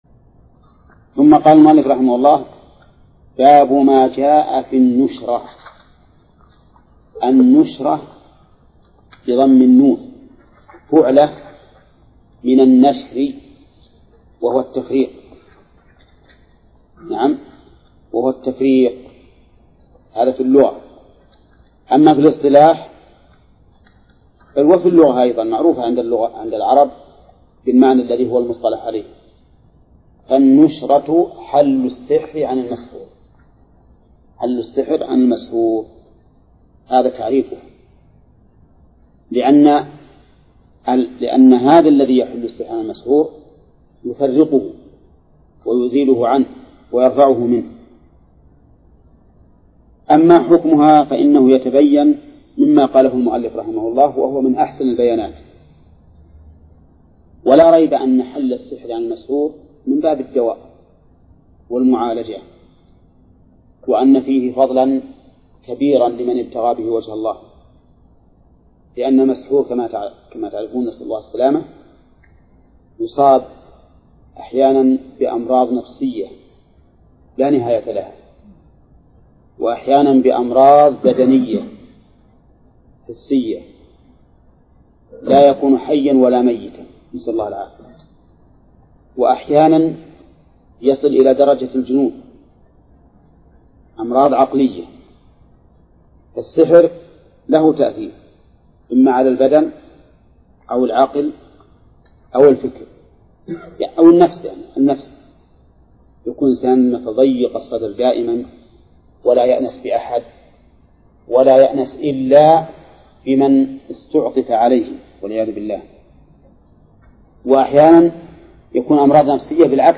درس (27) : من صفحة: (553)، قوله: (باب ما جاء في النشرة). إلى : صفحة: (570)، قوله: (ولهما عن أنس).